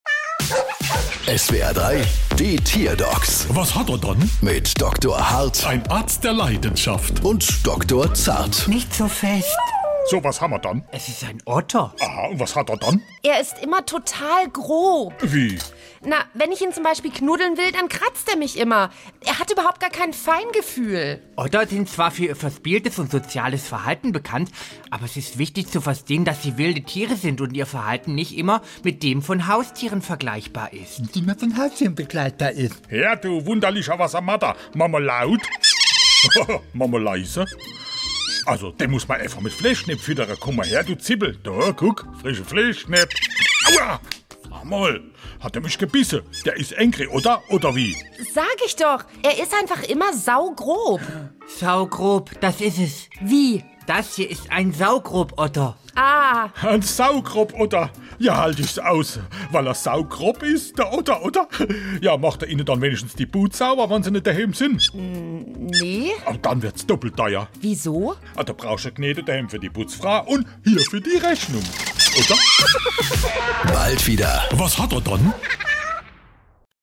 SWR3 Comedy Die Tierdocs: Otter ist grob